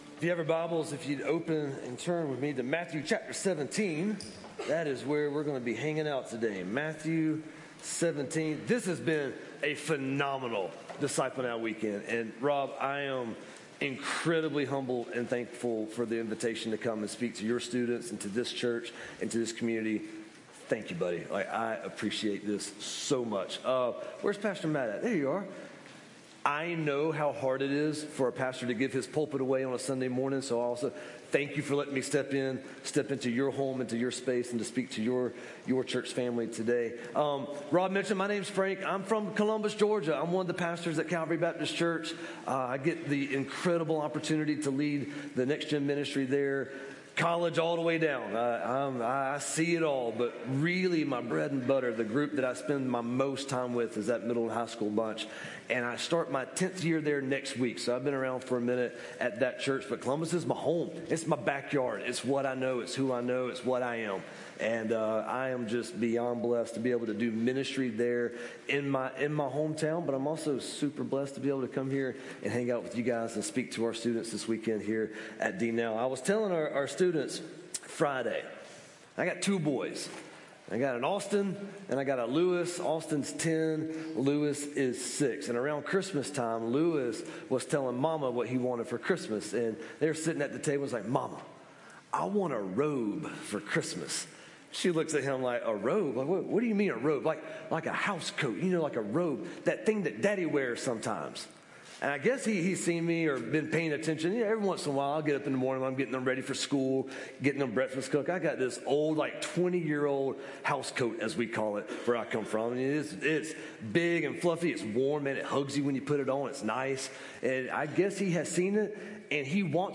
A message from the series "DNow."